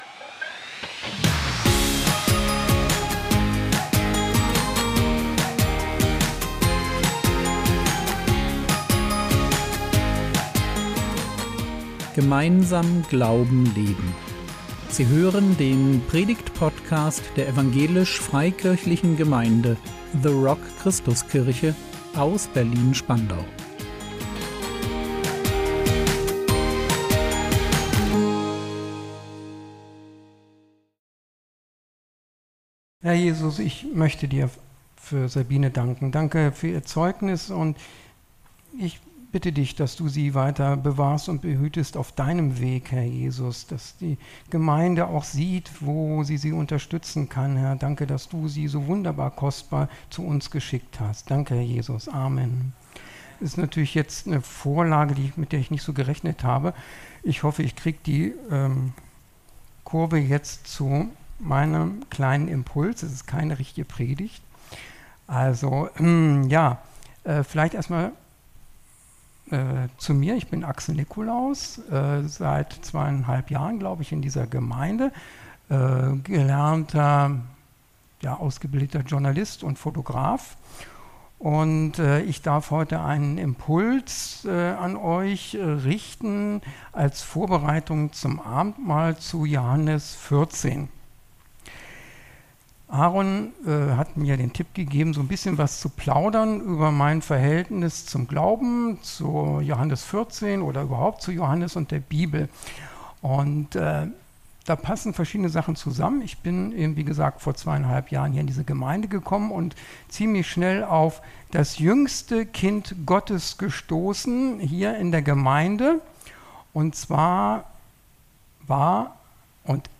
Der Weg und die Wahrheit und das Leben | 13.07.2025 ~ Predigt Podcast der EFG The Rock Christuskirche Berlin Podcast